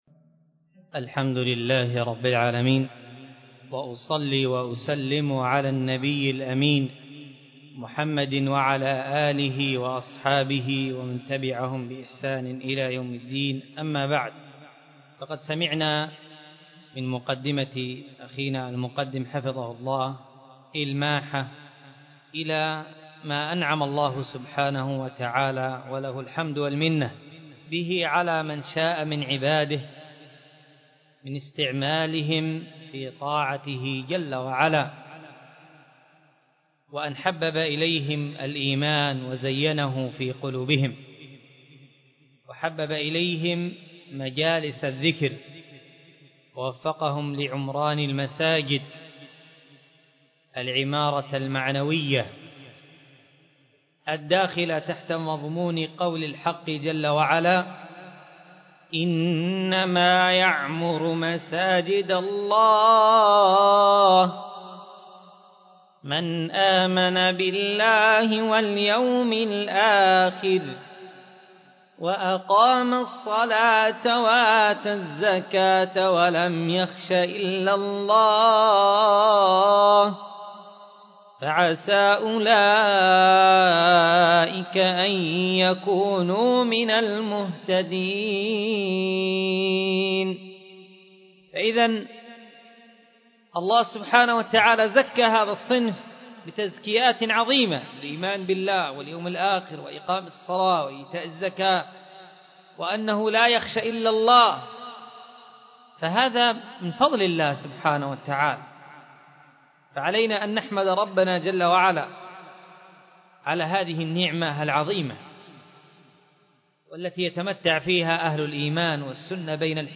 مسجد الخير الممدارة بلك 14 \ خلف مكتب موطن محافظة عدن حرسها الله